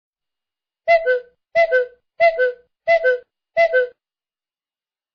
Ringetone Gøgefugl
Kategori Alarm